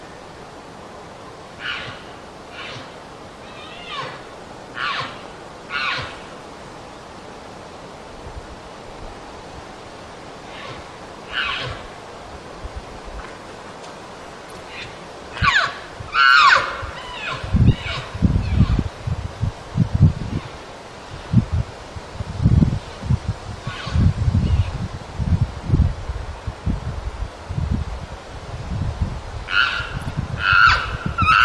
estrusbuzzbymouth2.mp3